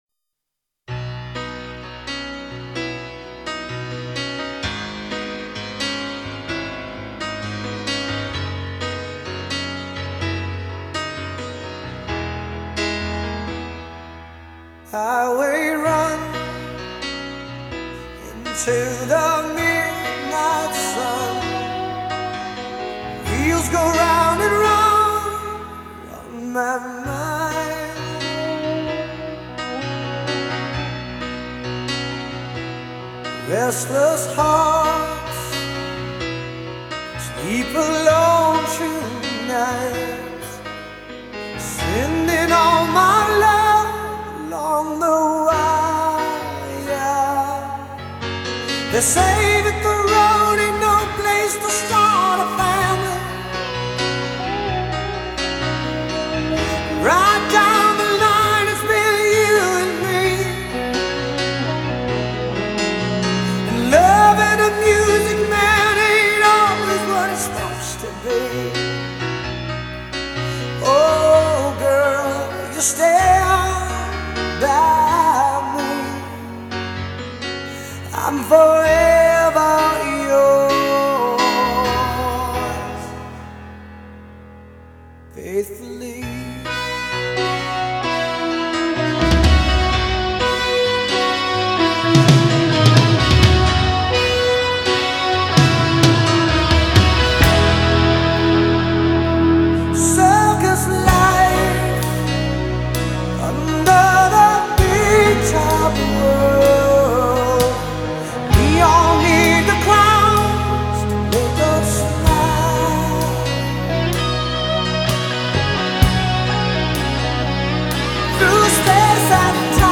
他们创造了一种主流摇滚风格。
同样一头长发,嗓音高亢.